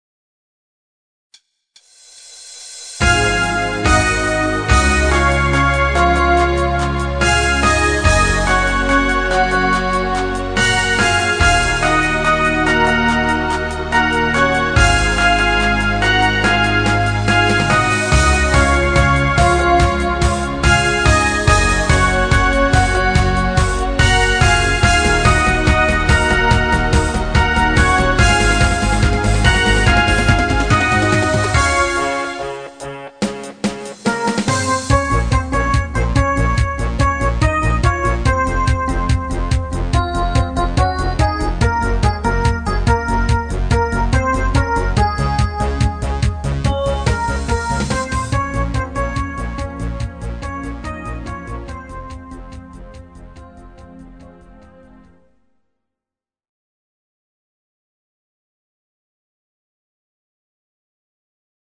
Genre(s): Deutschpop  Partyhits  |  Rhythmus-Style: Discofox